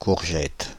Ääntäminen
IPA: [kuʁ.ʒɛt] Paris